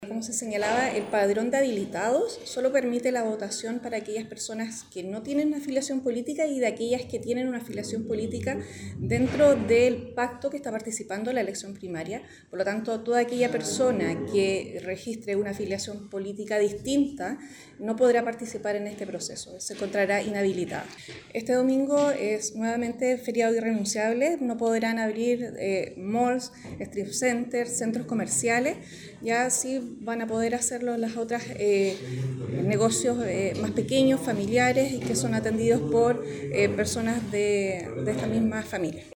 La directora regional de Servel, Pamela Manríquez, entregó detalles del proceso, exponiendo que “en esta oportunidad los colegios escrutadores no van a funcionar en los locales de votación, sino que en otras instalaciones públicas”. A ello agregó quiénes podrán votar y cómo funcionará el feriado irrenunciable.